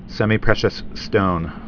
(sĕmē-prĕshəs, sĕmī-)